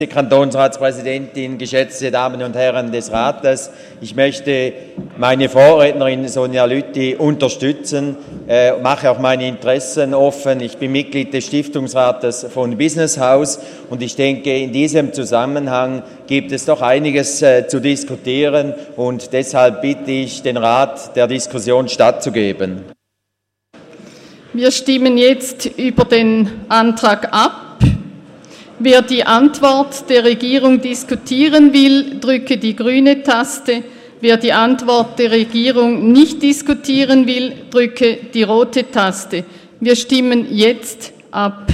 20.9.2017Wortmeldung
Session des Kantonsrates vom 18. bis 20. September 2017